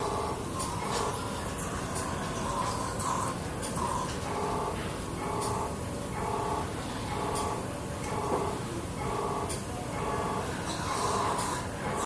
Hospital Ambience and Machine Breathing